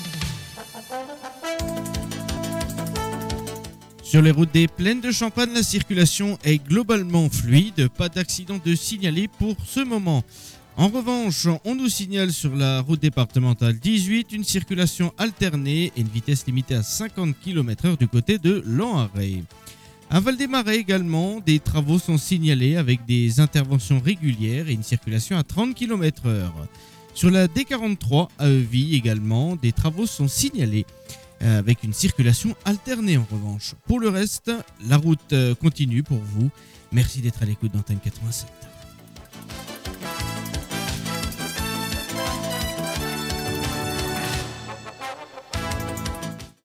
Bienvenue dans l’InfoRoute des Plaines – votre bulletin circulation du matin !